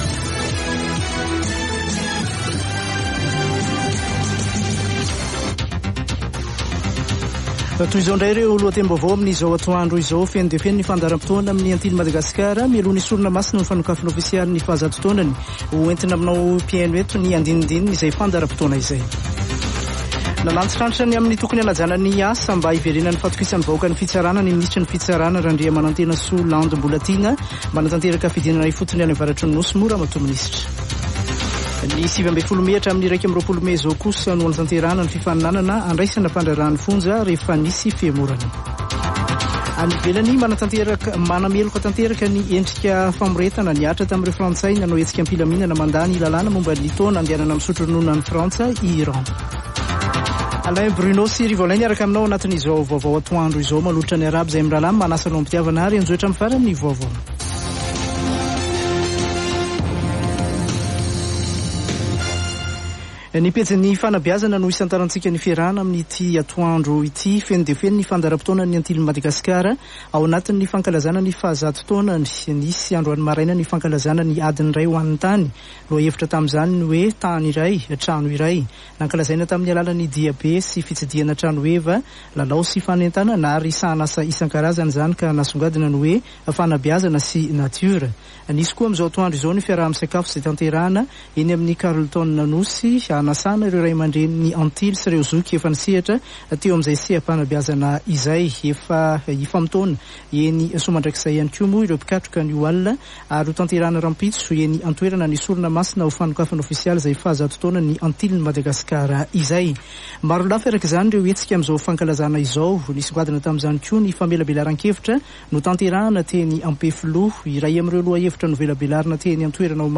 [Vaovao antoandro] Sabotsy 25 marsa 2023